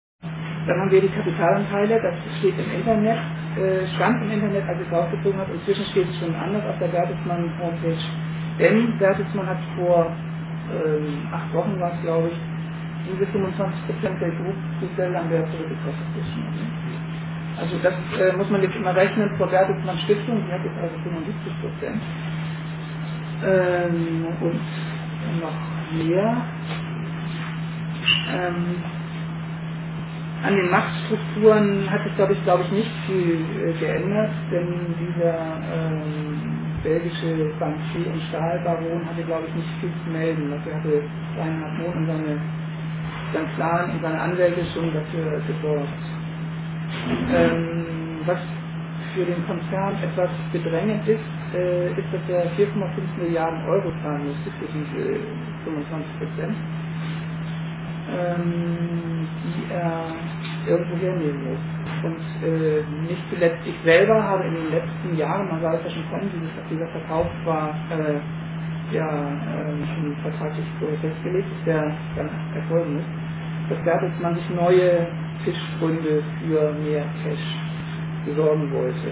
Attac-AG "Privatisierung Nein!", GEW BV-Ffm, ver.di FB 5 (Ffm), Jusos Ffm: hatten ins Gewerkschaftshaus eingeladen.
Über 80 Besucher erlebten einen interessanten Abend.